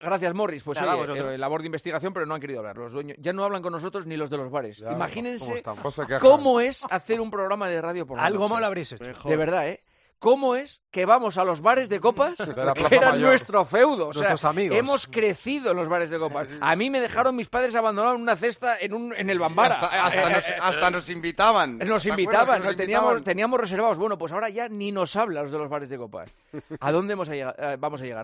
El presentador de 'El Partidazo de COPE' bromea durante el programa con una de las relaciones que siempre se ha emparejado al mundo de esta profesión